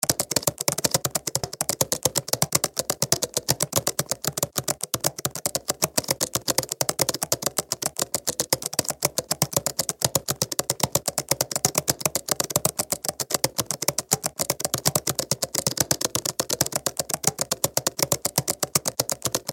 دانلود صدای کیبورد 4 از ساعد نیوز با لینک مستقیم و کیفیت بالا
جلوه های صوتی